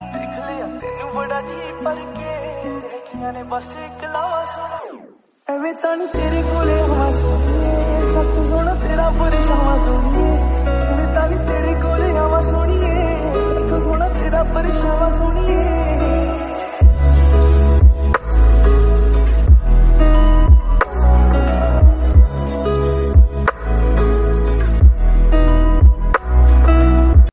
Dive into the soothing soundscape